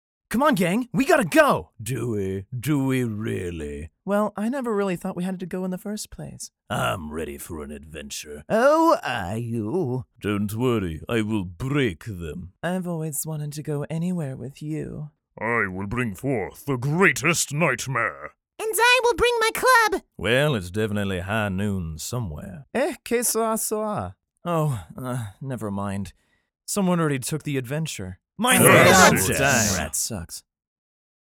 Classically Trained actor who specializes in Narration, commercial, video game, and animation voice over.
Sprechprobe: eLearning (Muttersprache):
Demo - Character Demo - Going on an Adventure (Clean) .mp3